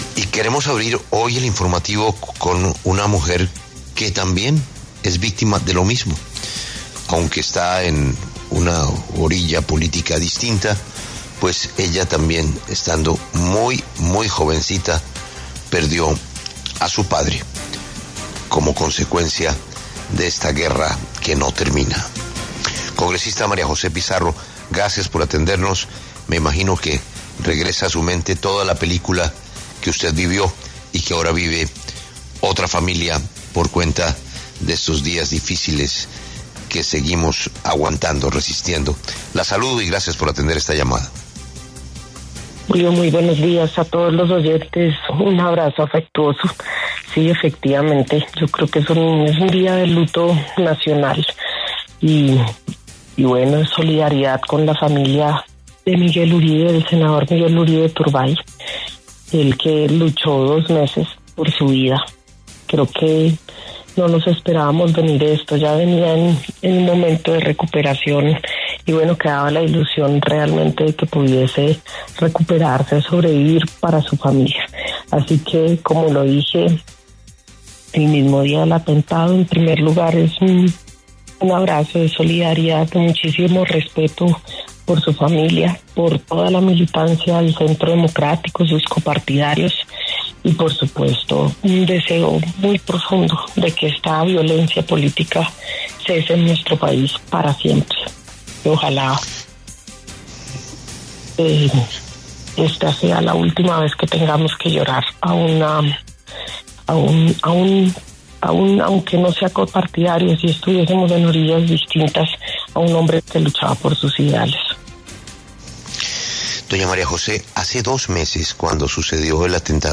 La senadora María José Pizarro habló en los micrófonos de La W, con Julio Sánchez Cristo, sobre la muerte del precandidato presidencial y senador Miguel Uribe.